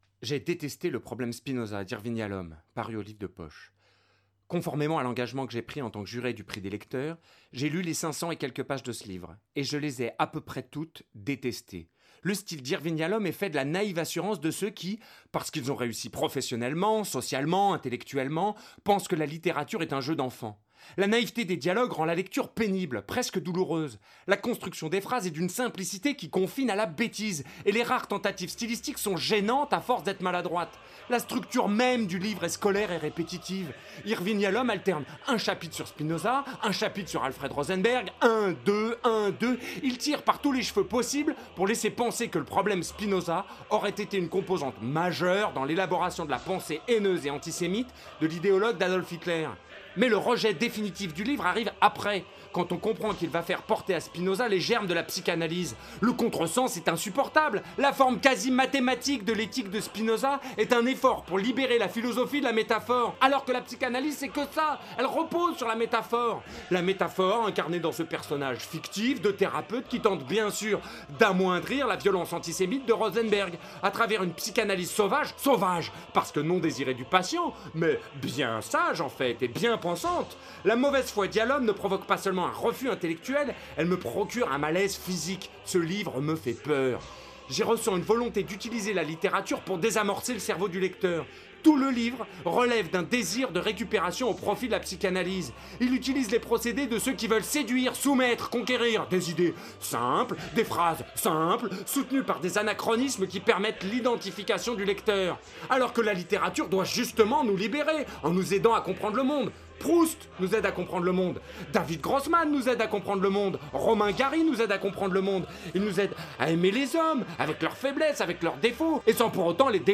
Le fond sonore est évidemment à prendre au second degré comme une critique de la violence de mon ton, et ceux qui ont reconnu l'hymne russe y verront une condamnation de l'attitude de Poutine, et non un soutien.